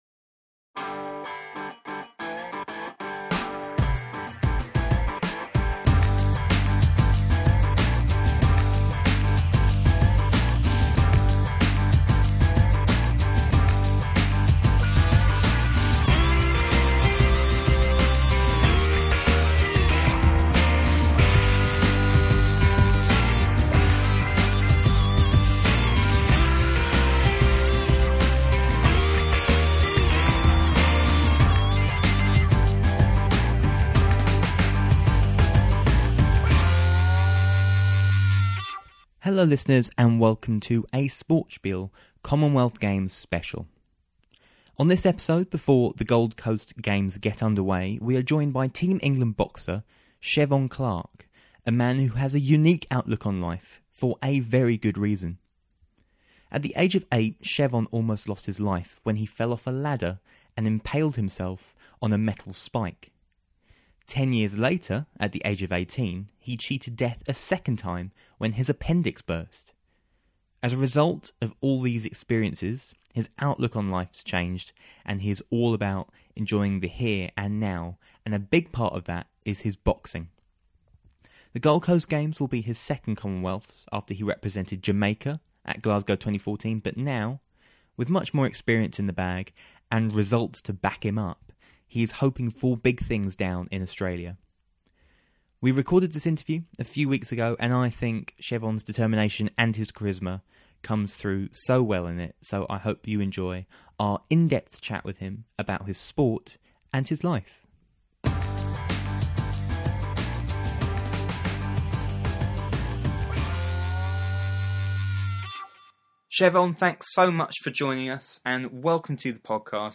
In this one-on-one interview he tells us what life was like growing up in Jamaica and in Kent, how he found his way into boxing, driving an HGV for two years and playing Call of Duty with Usain Bolt.